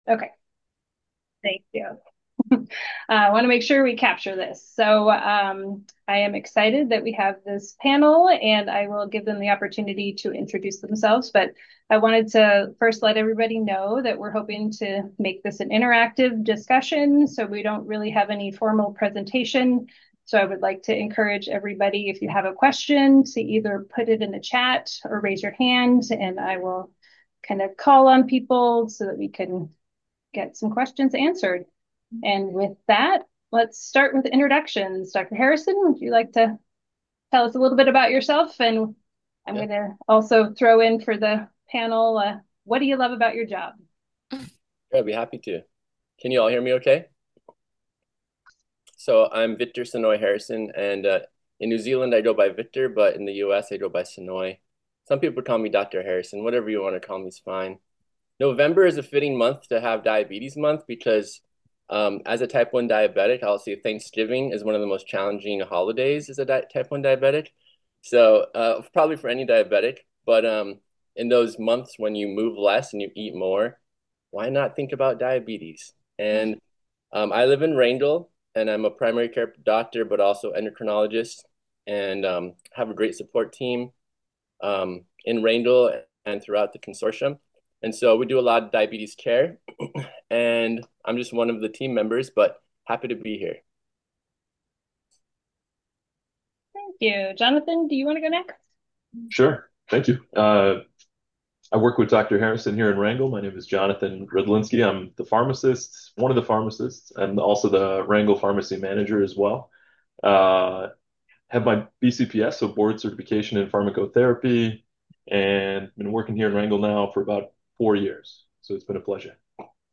Listen to the virtual panel discussion with SEARHC healthcare providers. They will discuss how our healthcare team works together to support patients with diabetes.